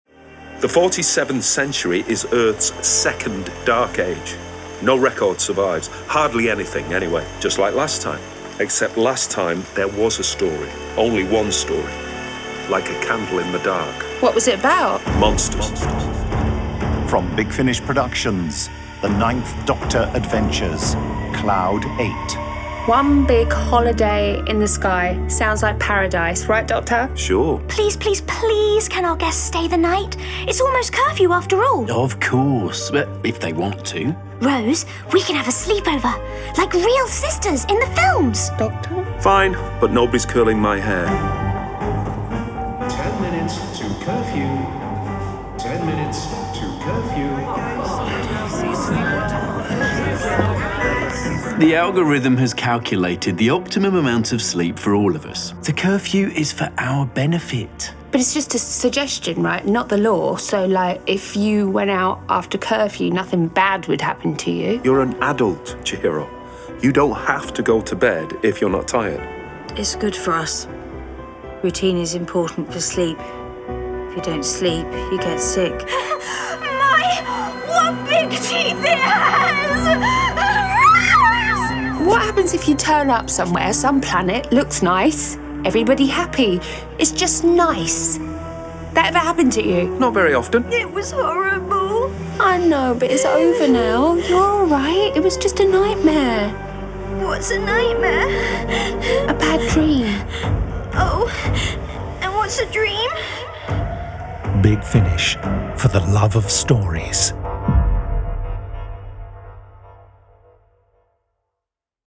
Starring Christopher Eccleston Billie Piper